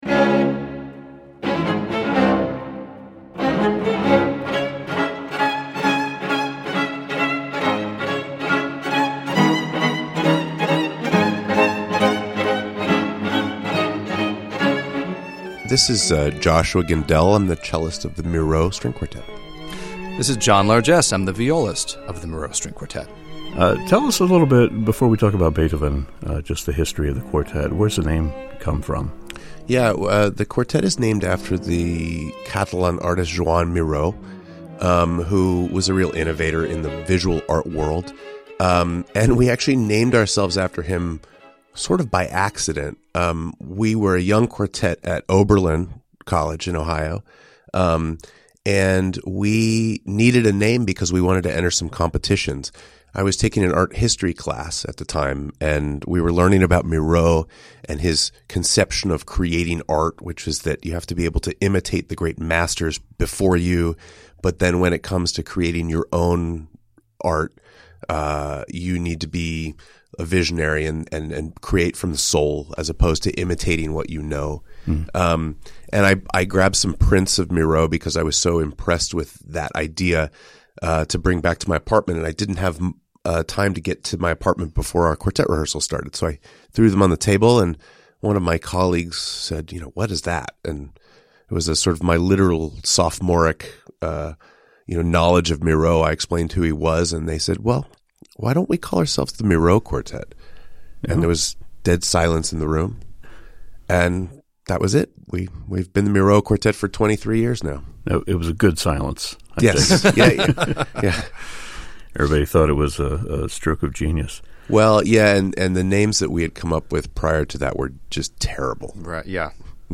Two members of the celebrated Miró Quartet talk about the group's history and the latest entry in their Beethoven cycle: the String Quartet Opus 131 in C sharp minor, one of the composer's most profound and enigmatic works.